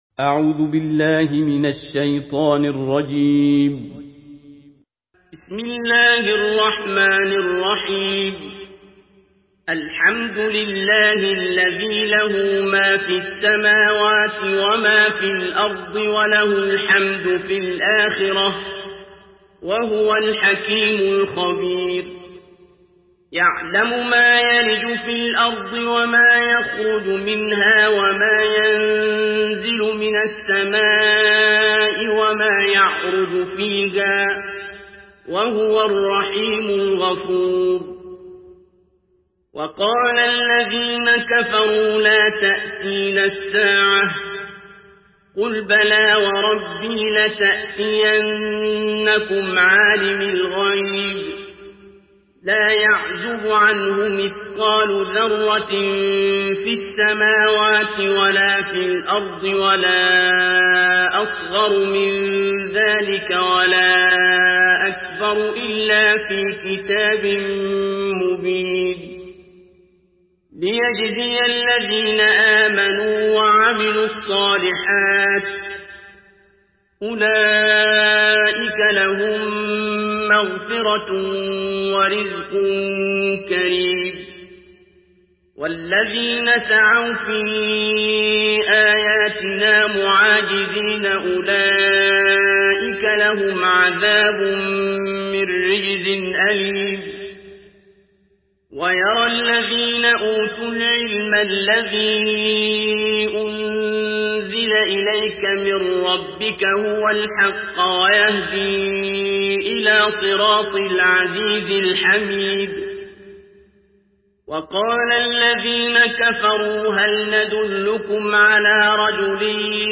Surah Saba MP3 by Abdul Basit Abd Alsamad in Hafs An Asim narration.
Murattal Hafs An Asim